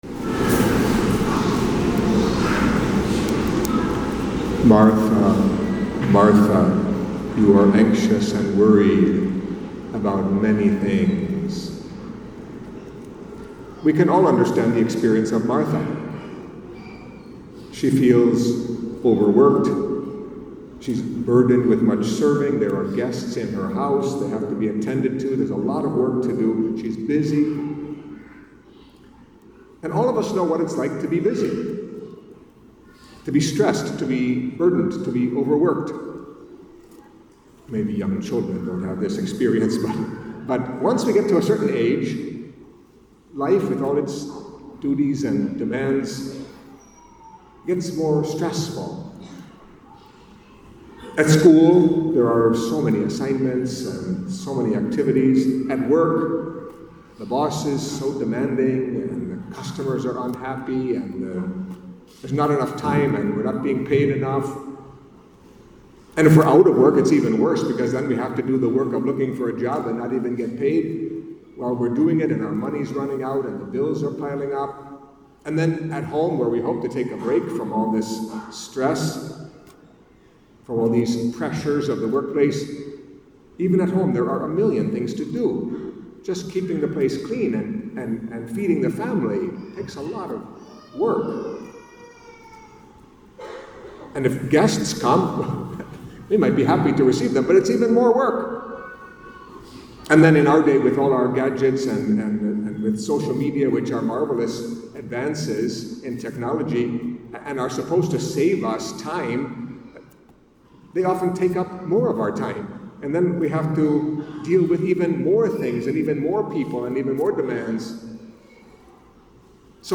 Catholic Mass homily for the Sixteenth Sunday in Ordinary Time